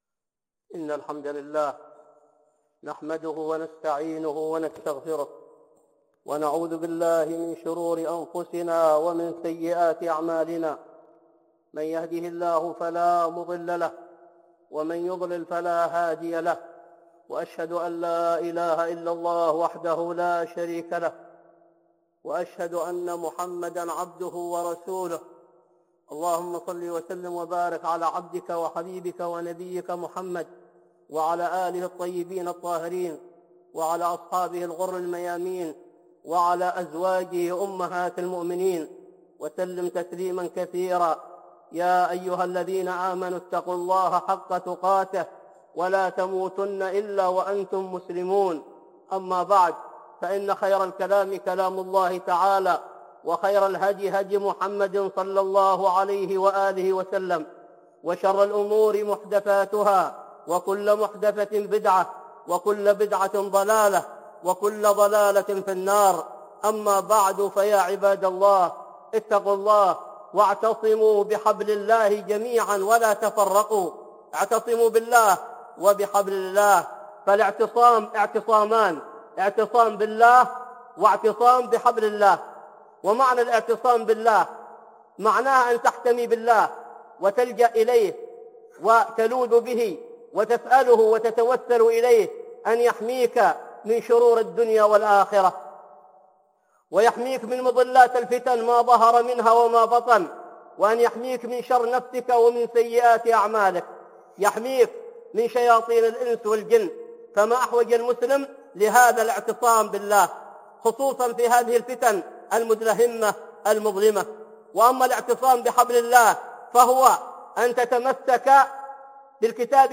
(خطبة جمعة) خطورة التعصب وأضراره على الدين